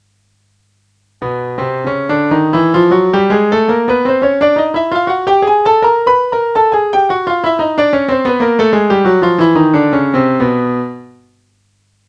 Chromatic_scale_from_B.ogg.mp3